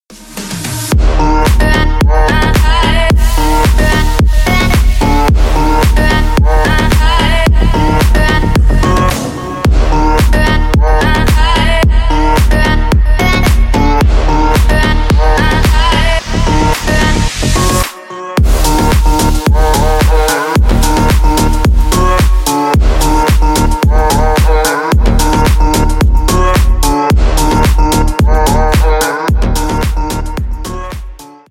Электроника
клубные
громкие